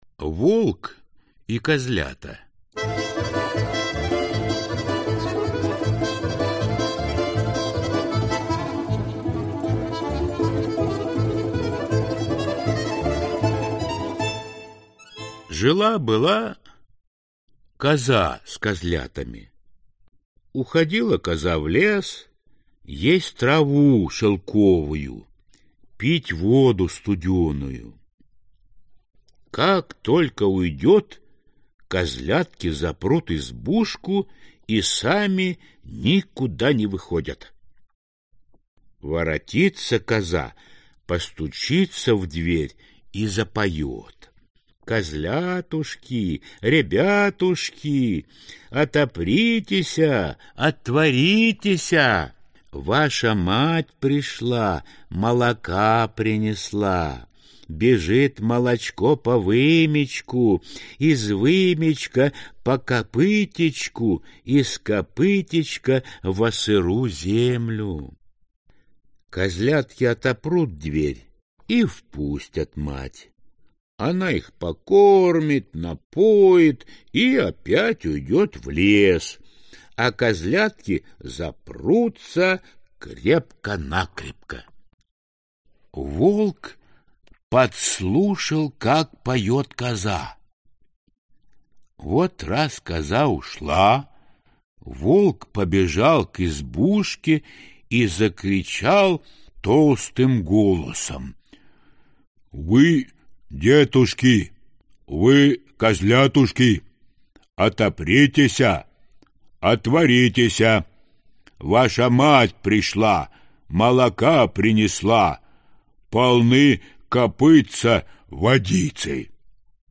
Аудиокнига 100 любимых маленьких сказок | Библиотека аудиокниг